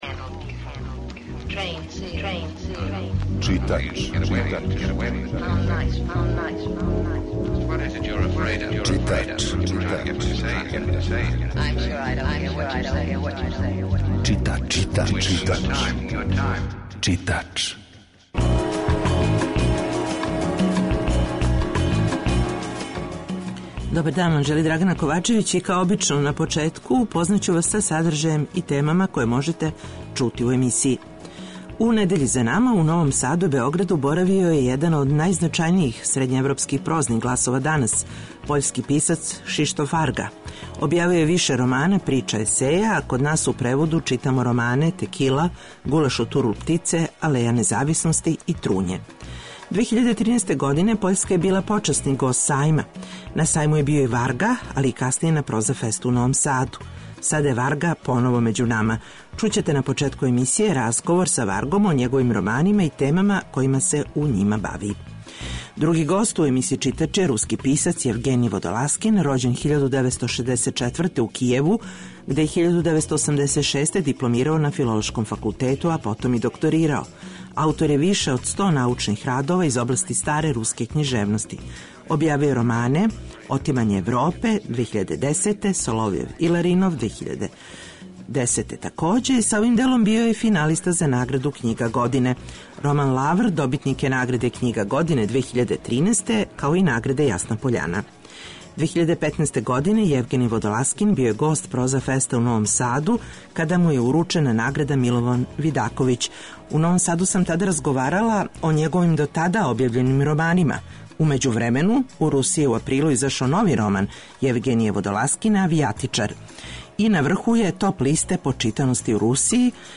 У емисији Читач, чућете разговор са Кшиштофом Варгом.